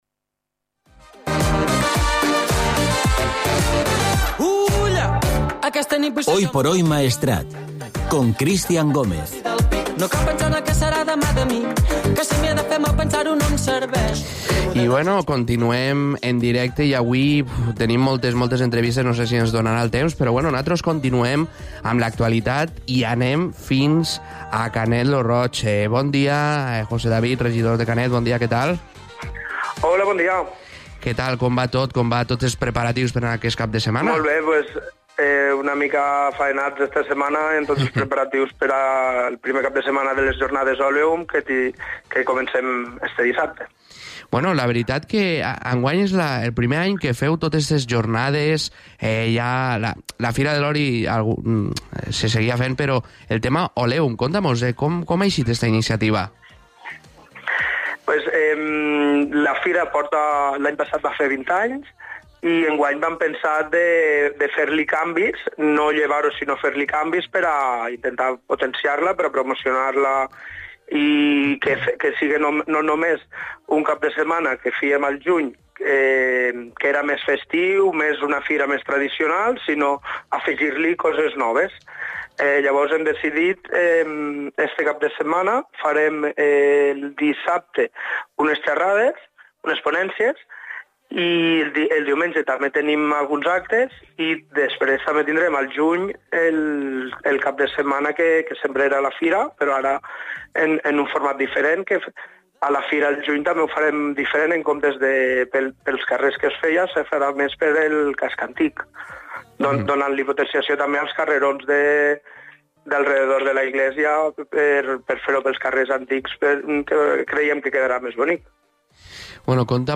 Podcast | Entrevista José David Coloma regidor de Canet lo Roig per les jornades Oleum